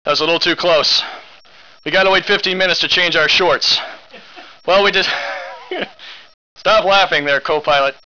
LP2-2_SN_03_OUTTAKE.wav